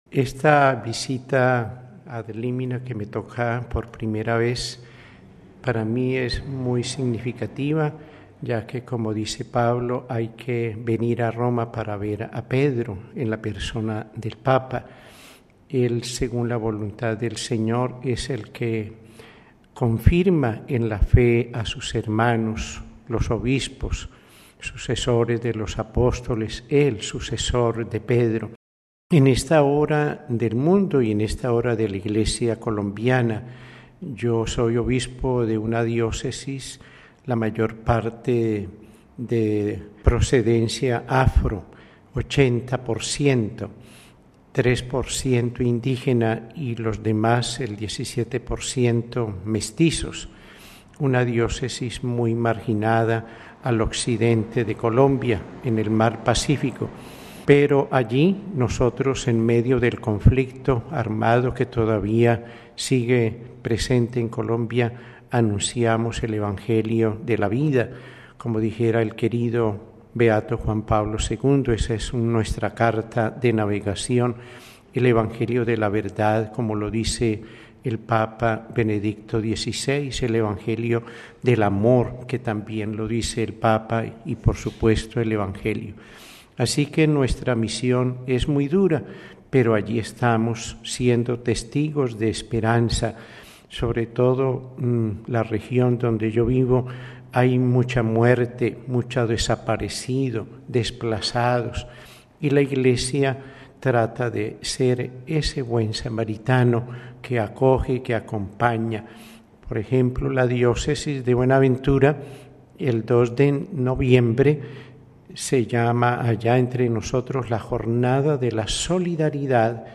Sobre la importancia de estos nuevos encuentros con el Papa y los dicasterios, escuchemos a mons Héctor Epalza, obispo de Buenaventura (Audio): RealAudio